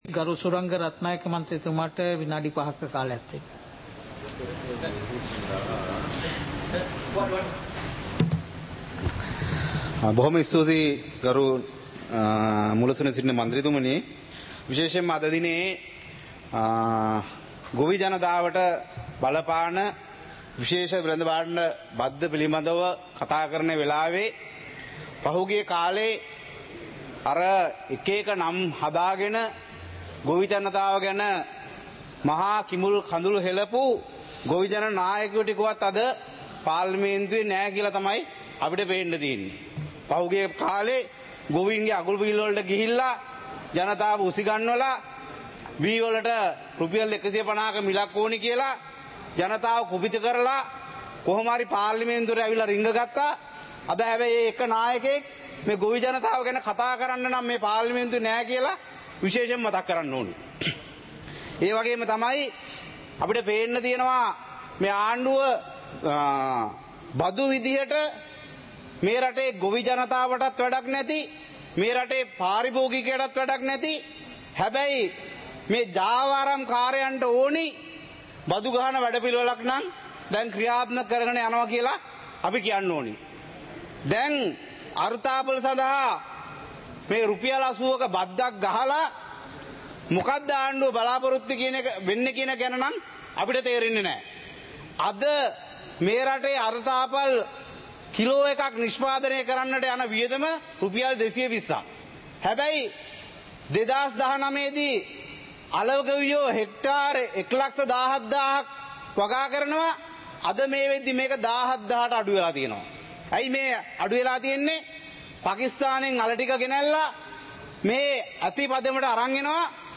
சபை நடவடிக்கைமுறை (2026-02-18)
நேரலை - பதிவுருத்தப்பட்ட